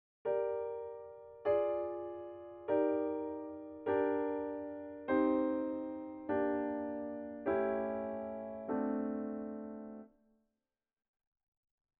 The progression above has a series of descending chords, in both root position and inversions.